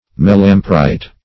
melampyrite - definition of melampyrite - synonyms, pronunciation, spelling from Free Dictionary
Search Result for " melampyrite" : The Collaborative International Dictionary of English v.0.48: Melampyrin \Mel`am*py"rin\, Melampyrite \Mel`am*py"rite\, n. [NL.